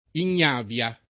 [ in’n’ # v L a ]